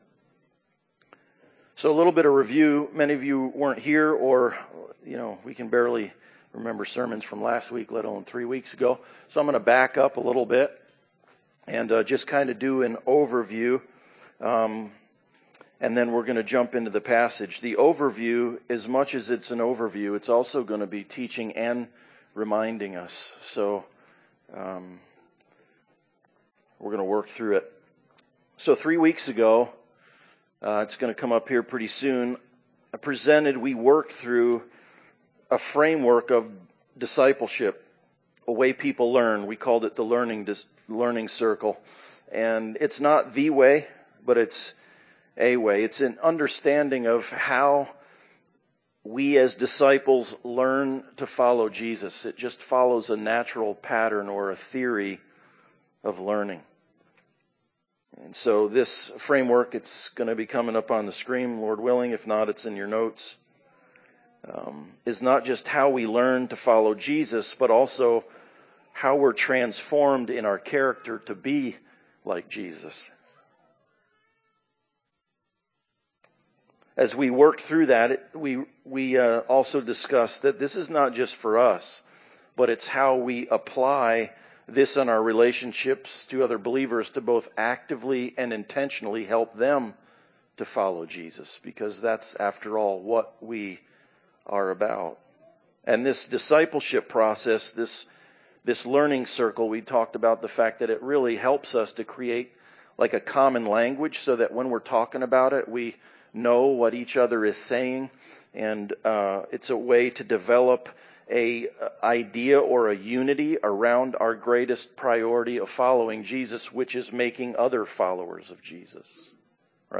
Hebrews 12:12-17 Service Type: Sunday Service Bible Text